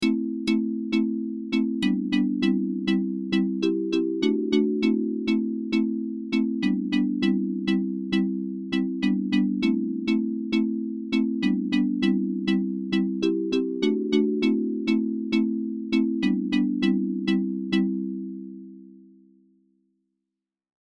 Das Marimba Preset des ESQ-1/SQ80 klingt ziemlich gut: